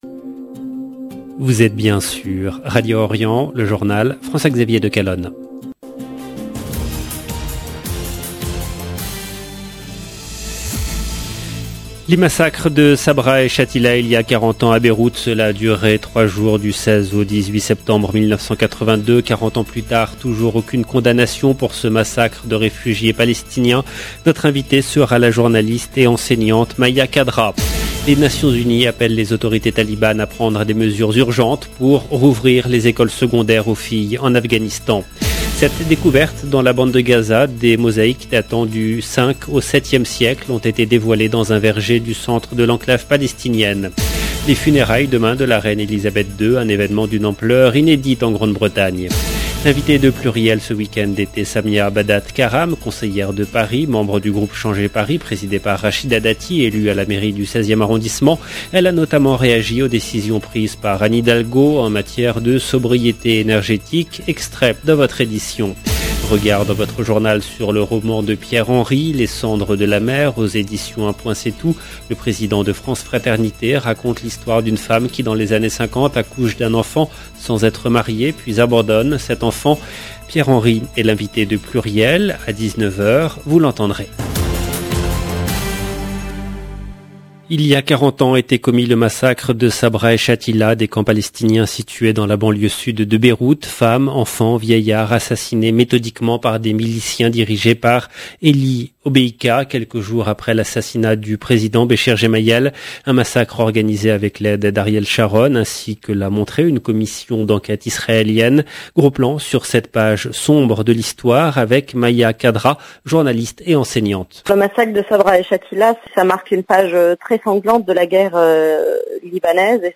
EDITION DU JOURNAL DU SOIR EN LANGUE FRANCAISE DU 18/9/2022